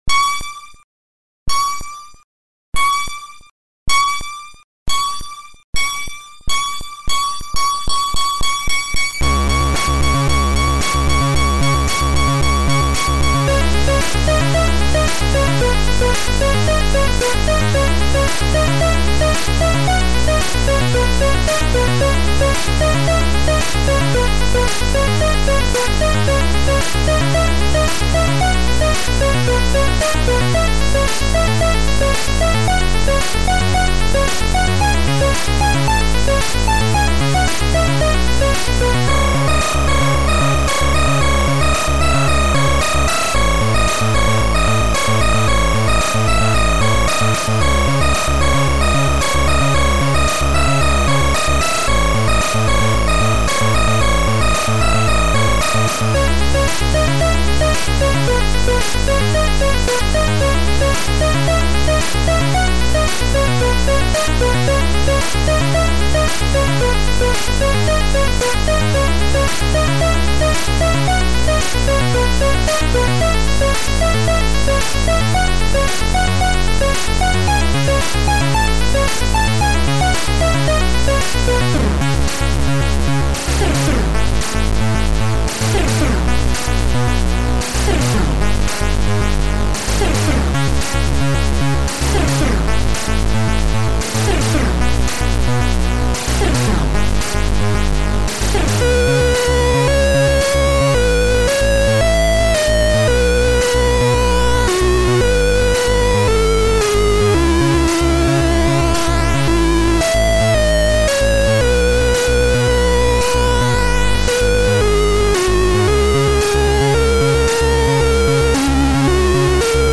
Hey, the song plays WAY too fast!
That is the original speed when played on a NTSC machine.